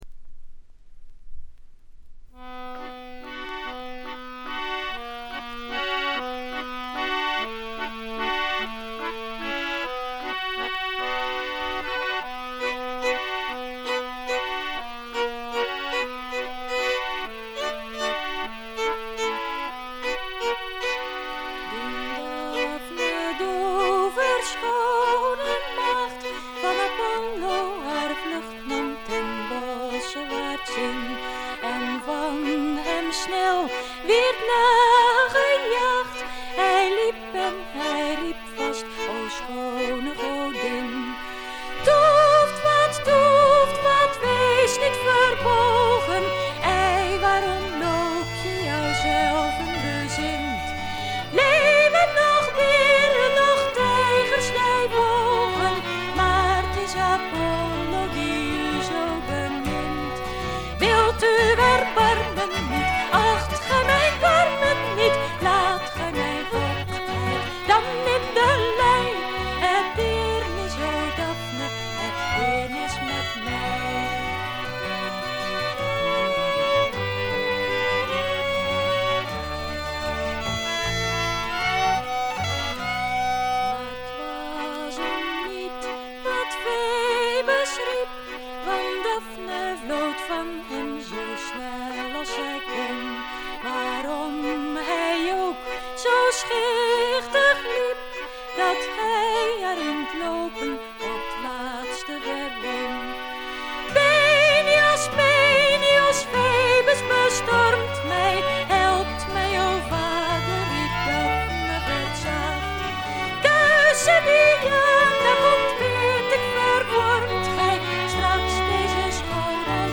A1中盤でプツ音2回、A2頭プツ音。
女性ヴォーカルを擁した5人組。
試聴曲は現品からの取り込み音源です。
Violin
Recorded At - Farmsound Studio